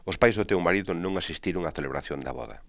os pájs Do téw maríDo nóN asistíroN a TeleBraTjón da BóDa.